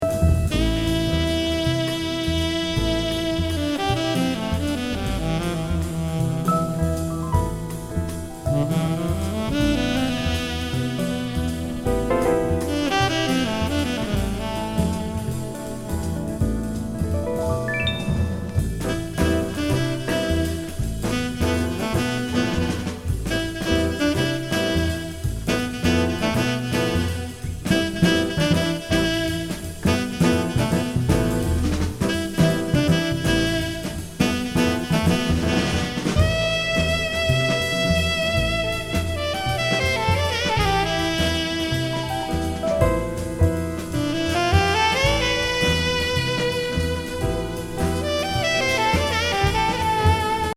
男女ツインヴォーカル・ブラジリアンムード・ソフトフォーカスジャズ！メロウ&スムース♥